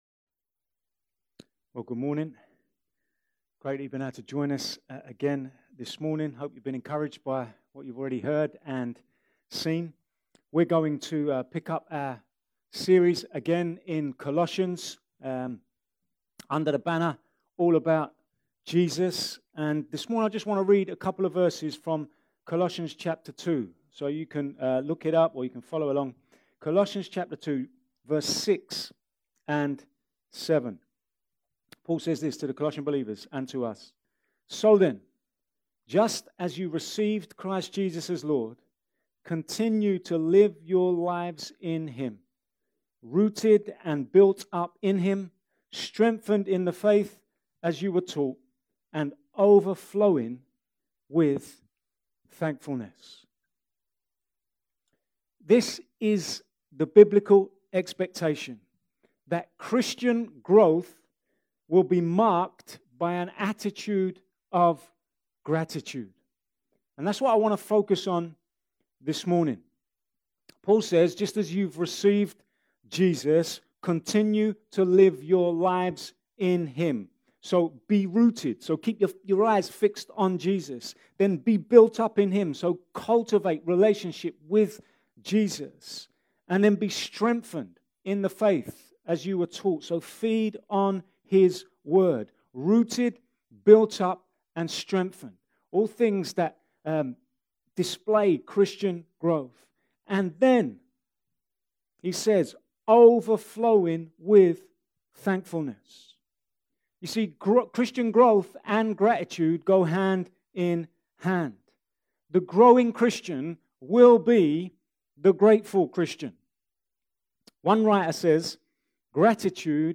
A message from the series "Sunday Morning." The biblical expectation is that Christian growth will be marked by an attitude of gratitude. In this message we consider the importance of gratitude in the Christian life and how we can cultivate it.